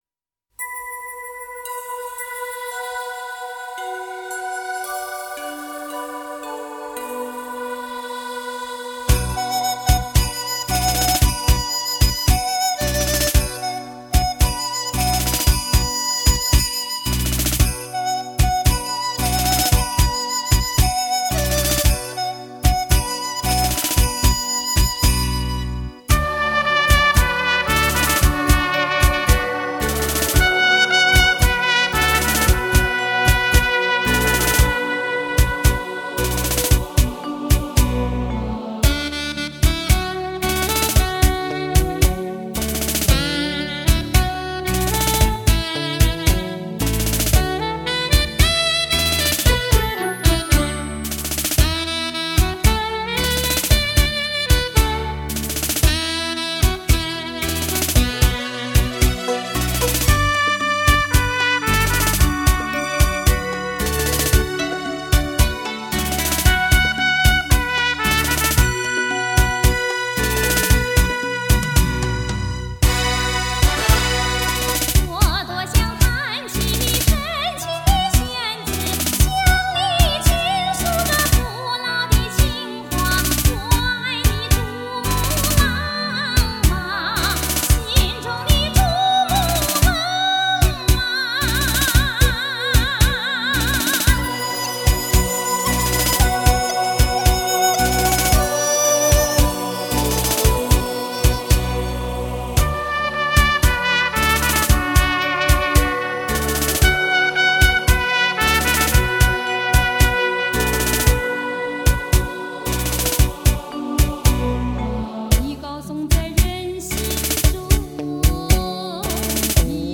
经典民歌唤起深情追忆，恰恰探戈舞出久违激情，
探戈